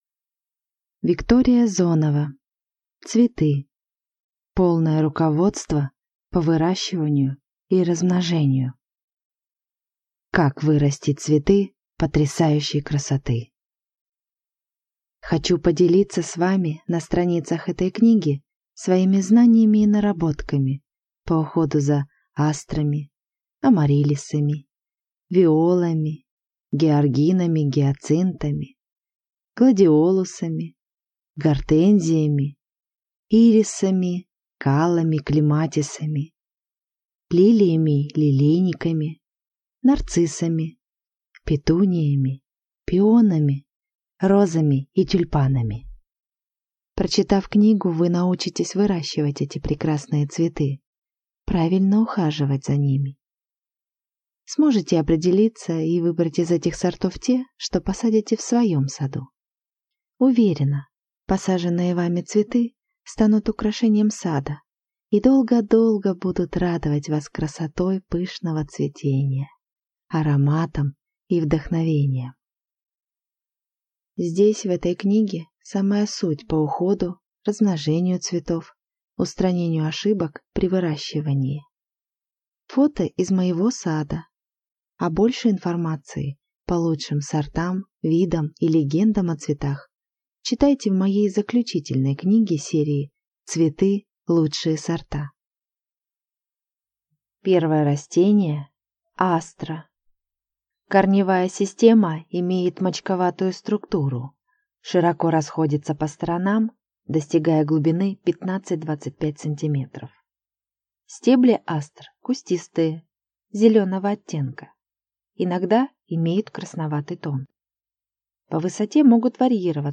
Аудиокнига Цветы. Полное руководство по выращиванию и размножению | Библиотека аудиокниг